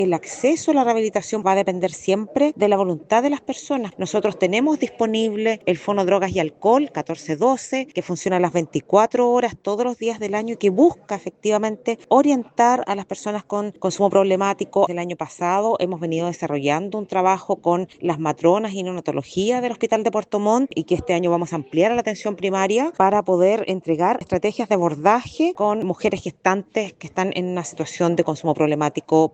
En esa misma línea, la directora regional del Senda, Maritza Canobra, afirmó que han debido aumentar la oferta de rehabilitación en la región dado el aumento de personas con consumo problemático.